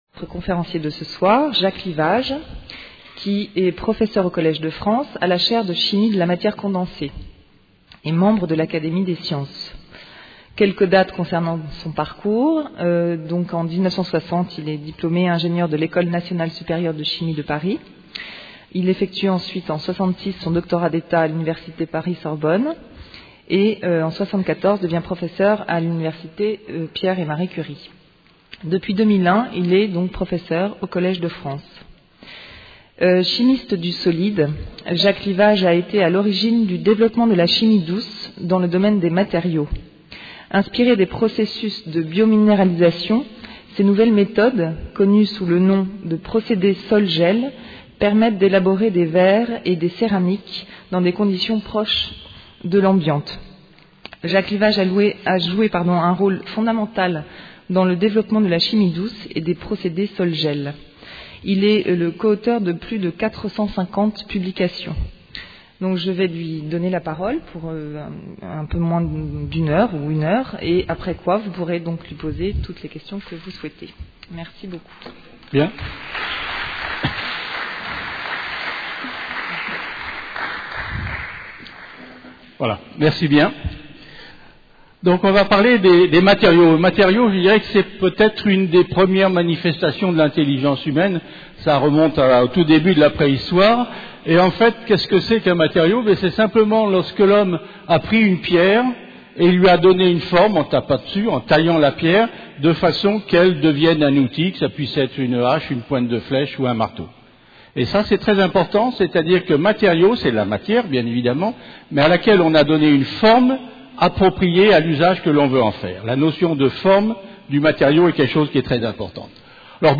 Une conférence UTLS du cycle : « La Chimie partout » du 21 au 29 mai 2011 à 18h30